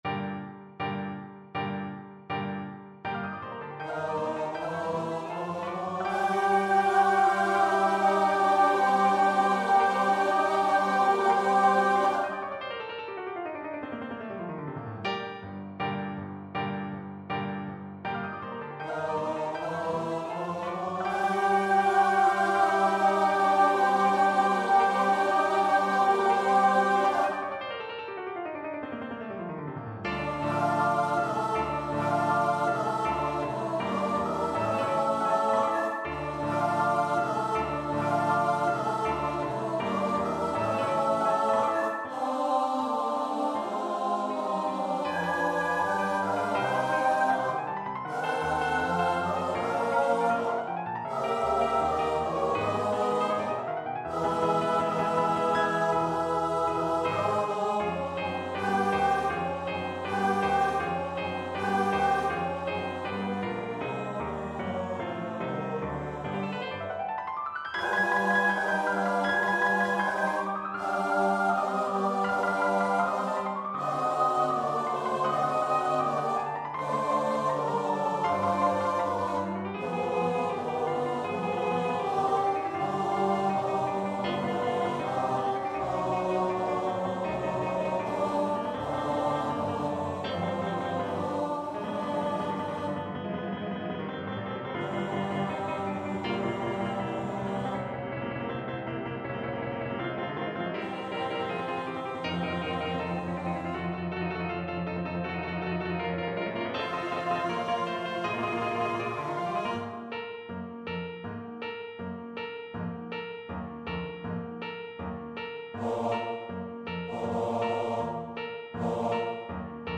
Dies Irae & Tuba Mirum (Requiem) Choir version
Choir  (View more Intermediate Choir Music)
Classical (View more Classical Choir Music)